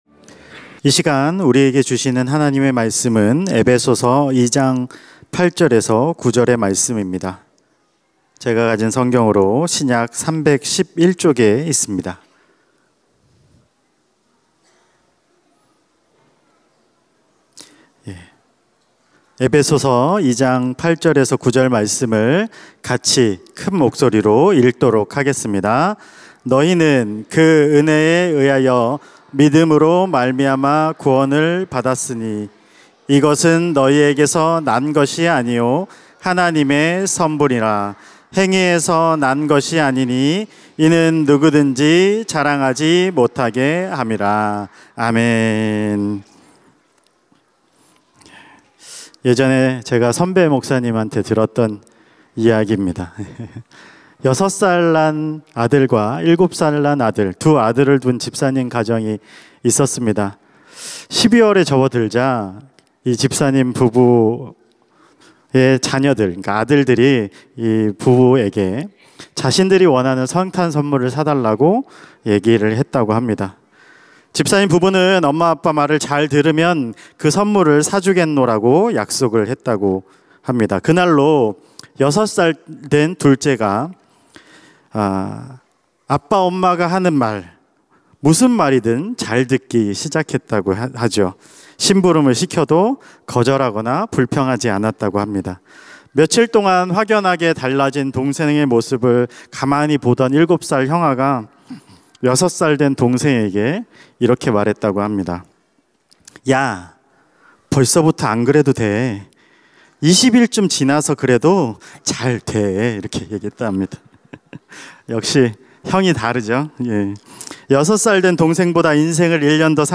찬양예배 - 아버지의 선물